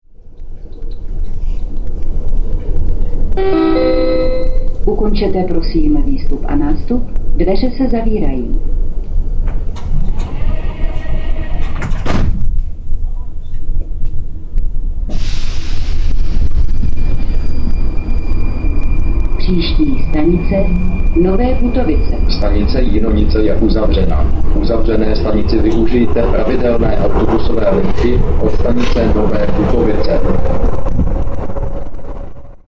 Informovanost cestujících je zajištěna formou hlášení přímo v soupravách metra.
- Hlášení po odjezdu ze stanice Radlická (směr Zličín) si